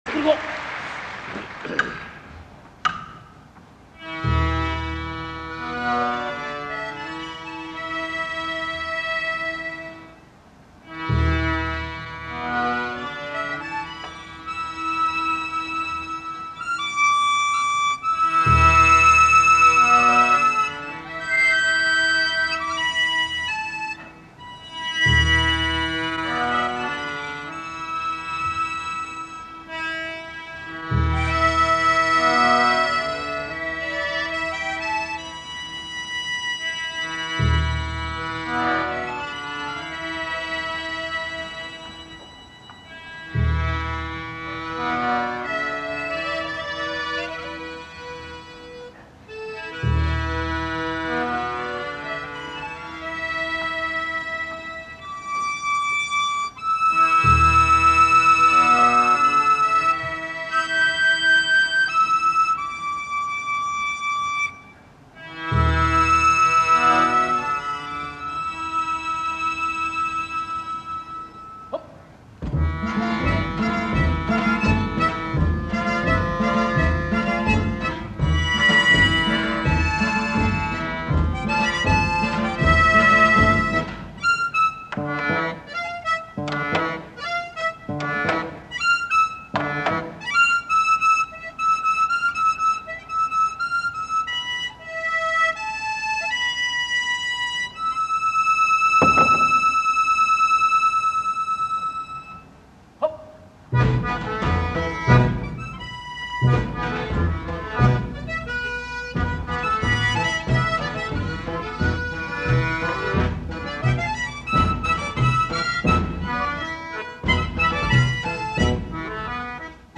Blue Bird Band
National Sanatorium Nagashima Aiseien, Okayama